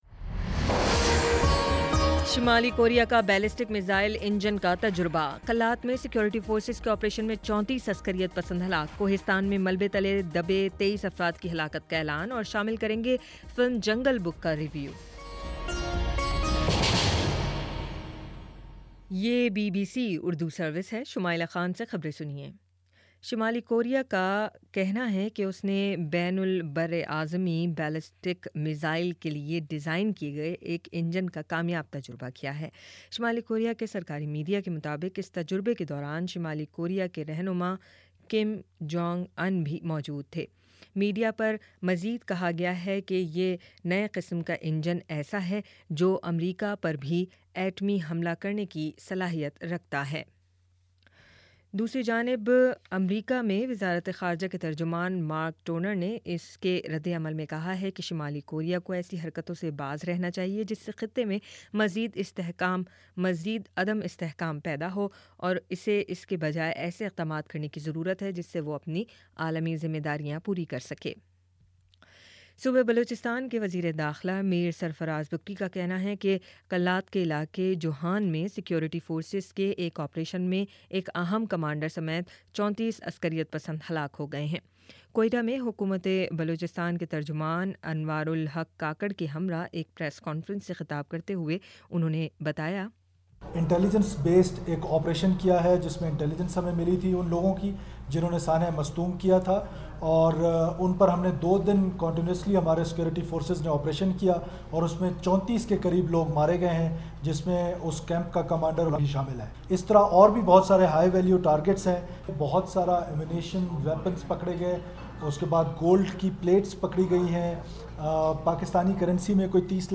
اپریل 09 : شام پانچ بجے کا نیوز بُلیٹن